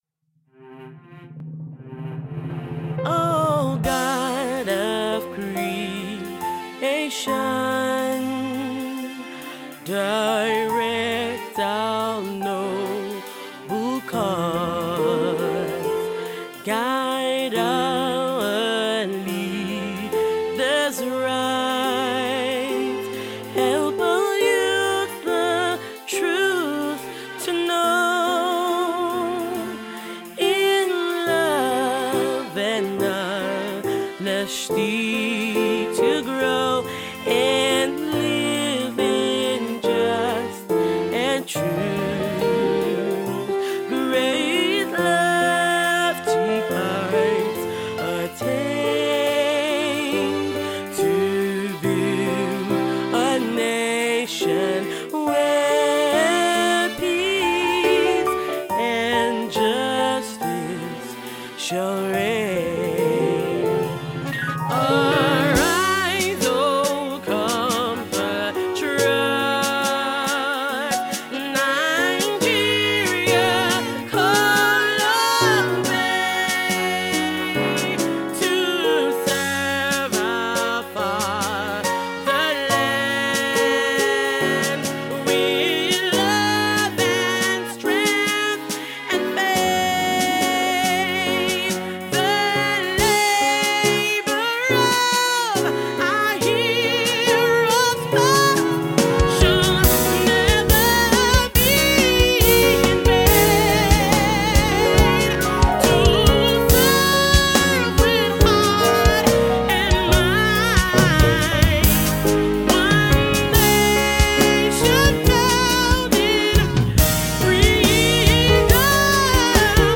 patriotic vibe